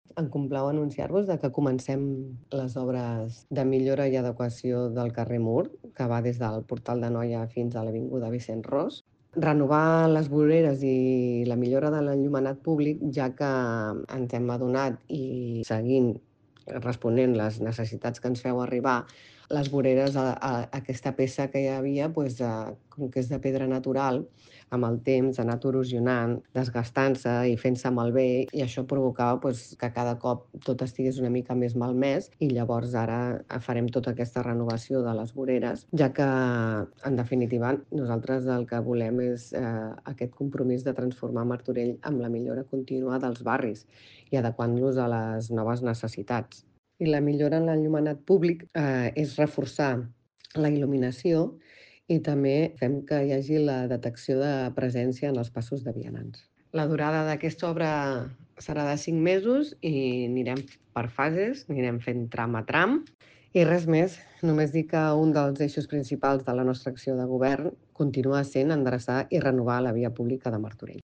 Elisabeth Plaza, regidora de Serveis Urbans i Via Pública